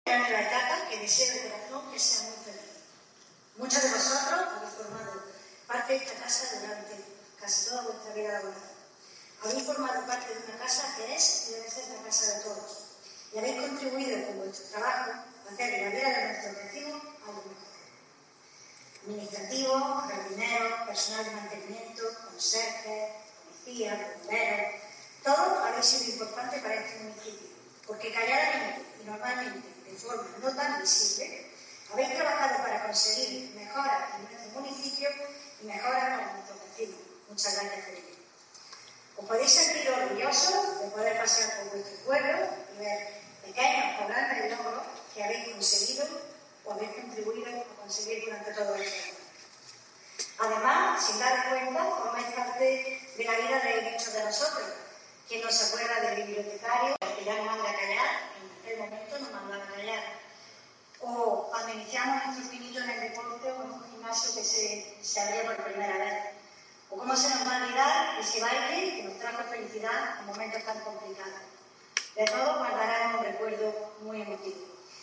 El acto se celebró en el auditorio de la Casa de la Cultura ante familiares y compañeros.
TENIENTE-ALCALDE-BEATRIZ-GONZALEZ-CERRO-ACTO-HOMENAJE-EMPLEADOS-JUBILADOS-21.mp3